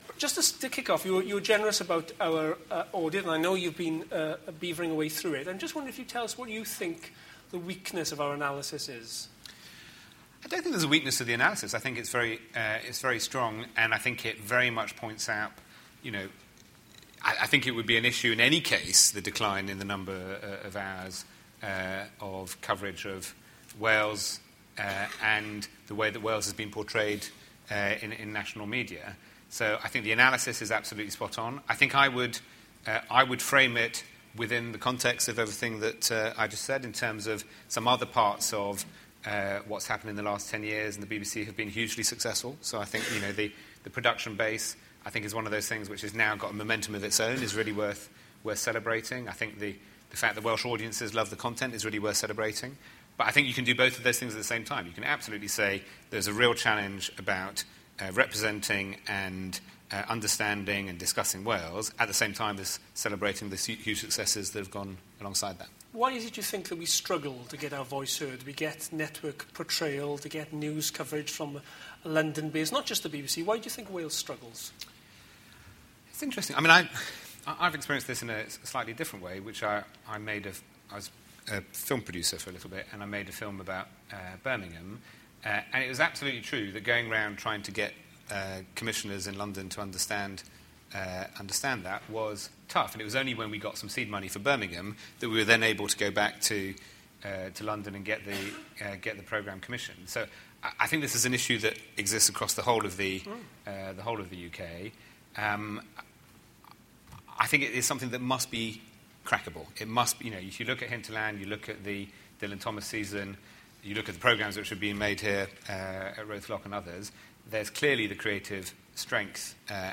IWA Director Lee Waters interviews James Purnell, BBC Director of Strategy, at the Cardiff Media Summit 2015. They discuss issues around Welsh English language TV provision and the representation of Wales on the BBC.